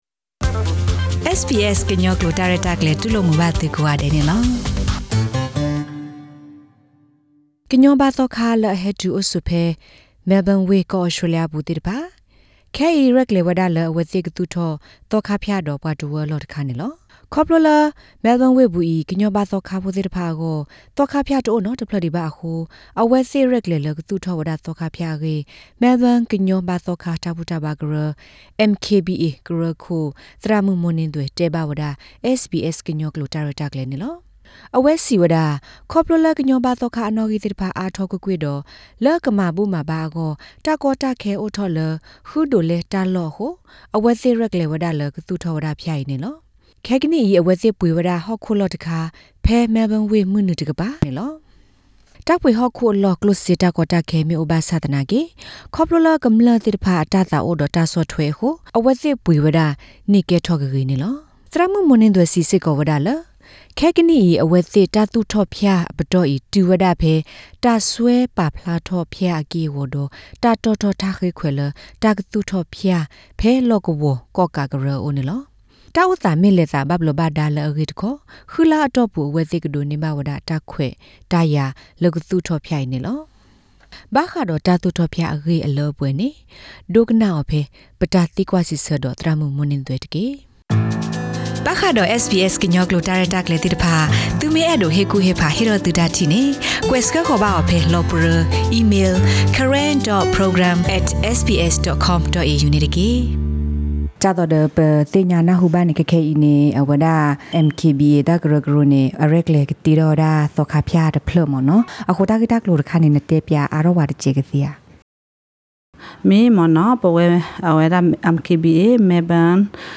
Have a listen the full interview in Karen for more information about the monastery.